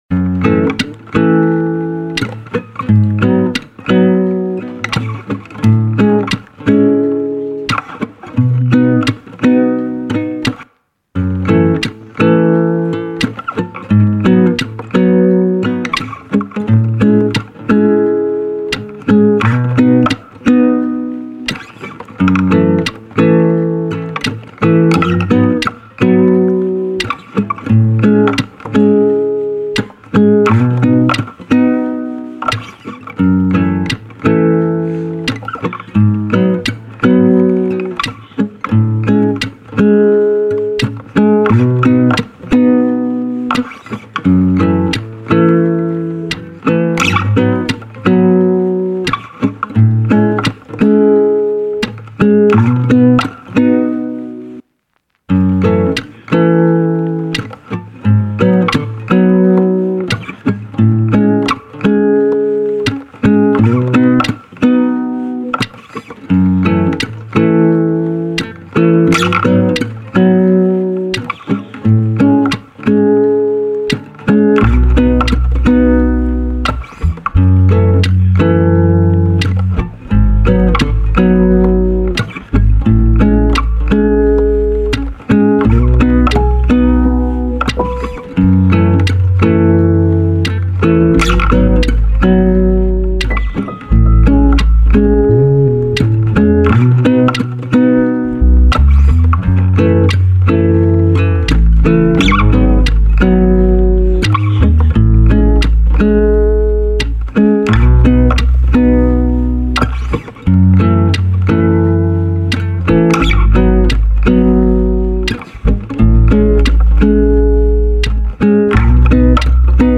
This is the official instrumental
R&B Instrumentals